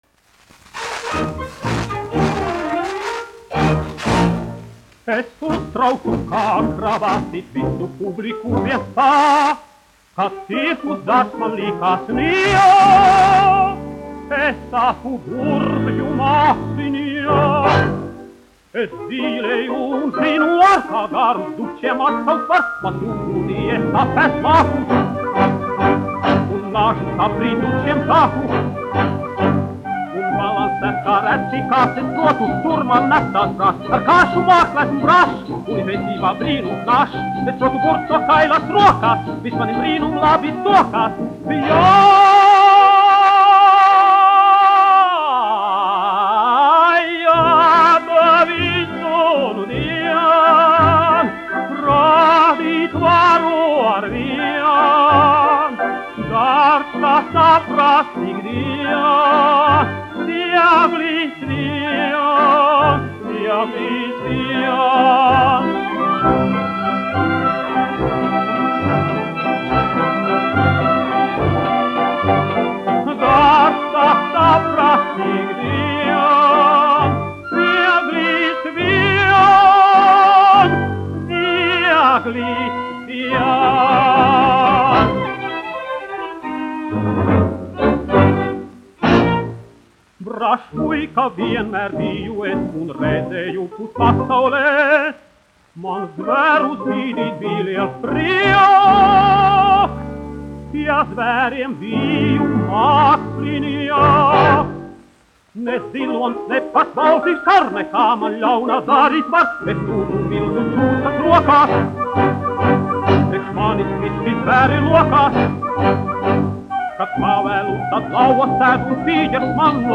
1 skpl. : analogs, 78 apgr/min, mono ; 25 cm
Operas--Fragmenti
Skaņuplate